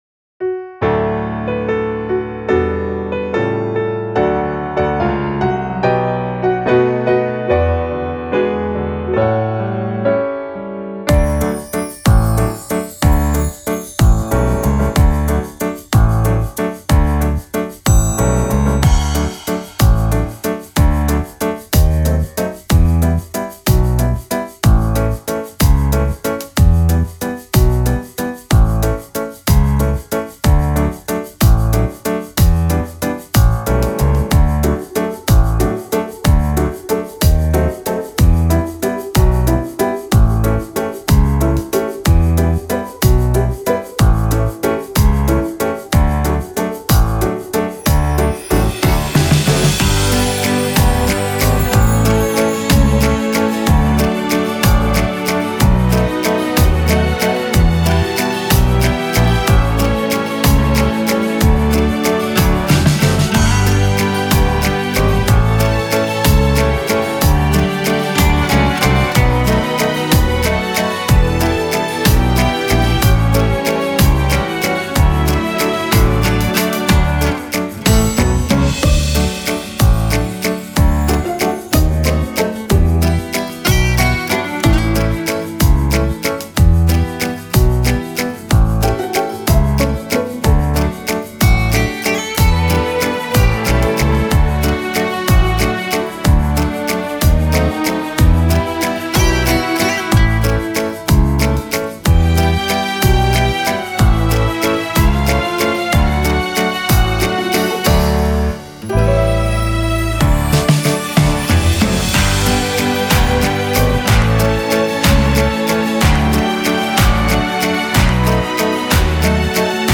„Mūsų dienos kaip šventė“ fonograma